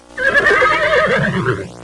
Horse (sick) Sound Effect
horse-sick.mp3